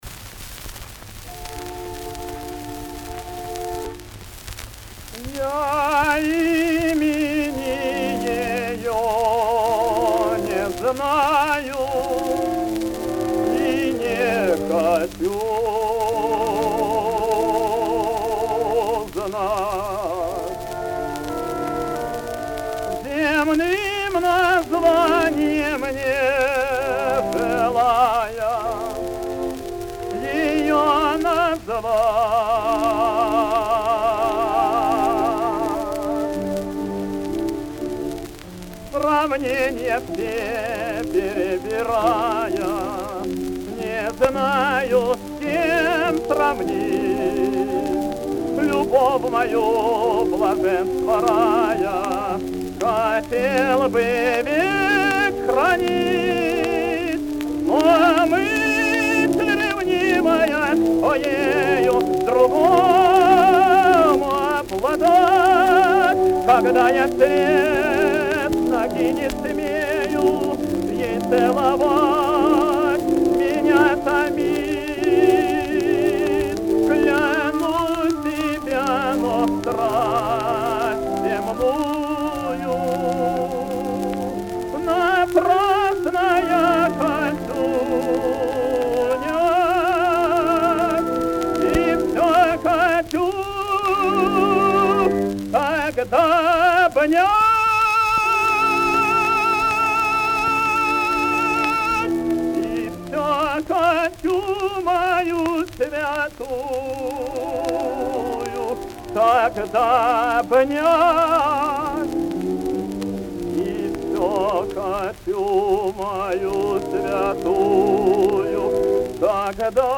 Опера «Пиковая дама». Ариозо Германа. Исполняет В. П. Дамаев.
Обладал сильным и гибким драматическим тенором широкого диапазона, ровно звучавшим во всех регистрах и позволявшим певцу успешно справляться с разнообразным лирико-драматическим и героическим репертуаром.